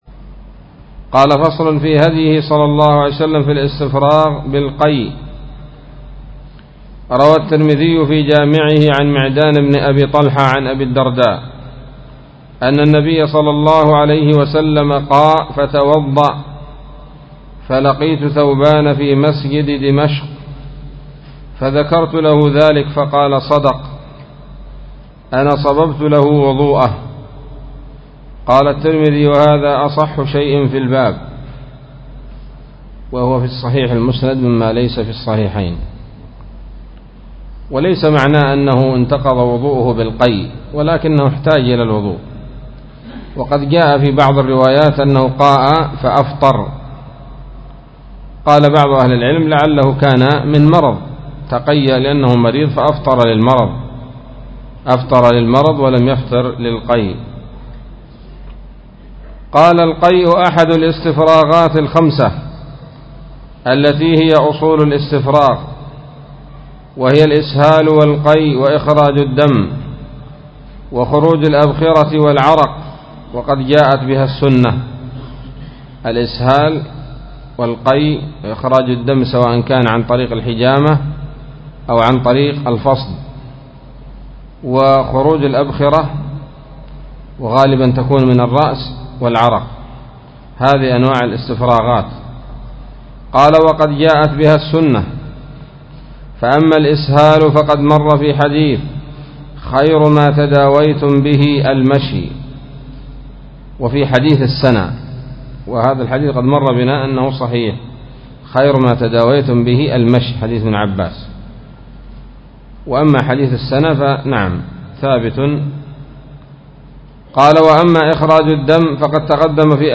الدرس السادس والثلاثون من كتاب الطب النبوي لابن القيم